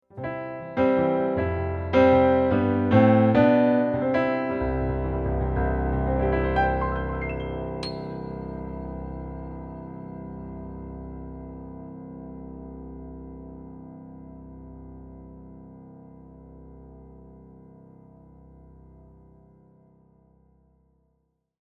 Die Klänge sind ausdrucksvoll, brillant und durchsetzungsfähig.
So wirkt der Klang insgesamt voller.
Casio GP 510 Vienna Grand
Der Klang ist transparent in den Mitten und bringt den nötigen Druck im Bassbereich mit.
gp_510_vienna_grand_1.mp3